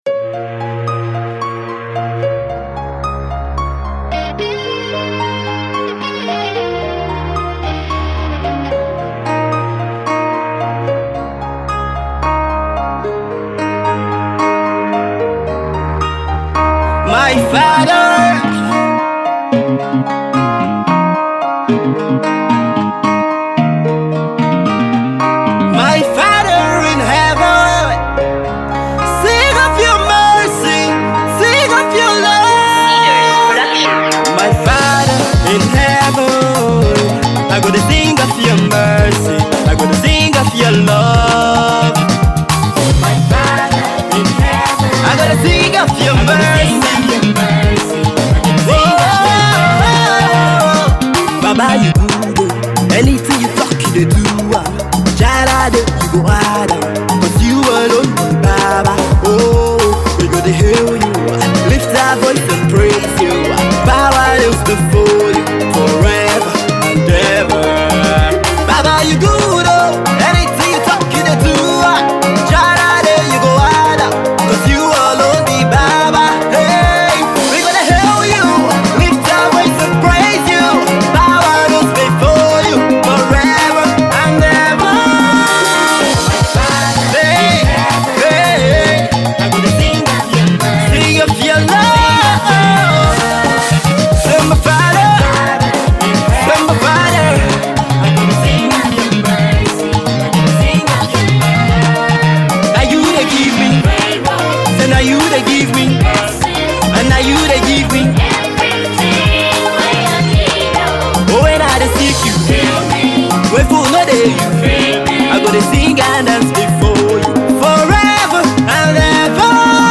Gospel minister